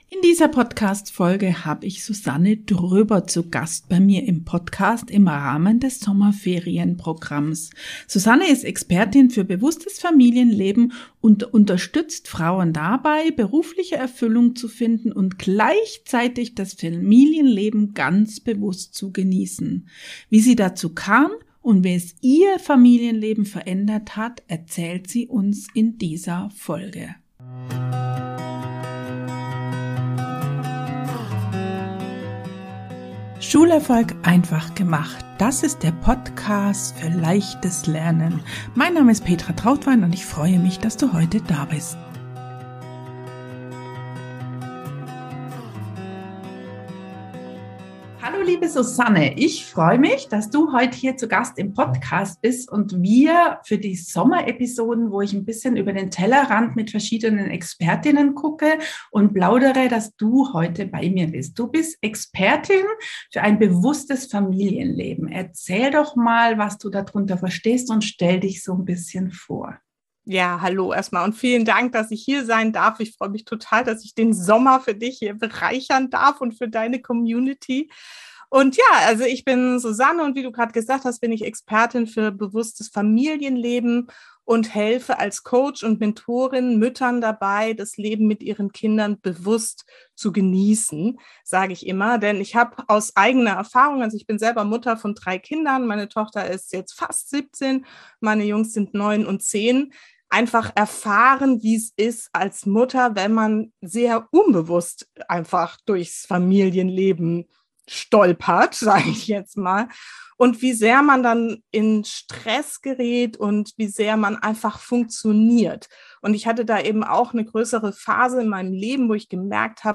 Dieses Interview ist prall gefüllt mit Tipps & Tools, wie du deine Freude als Mutter wiederfindest und deinen Familienalltag bewusster gestaltest.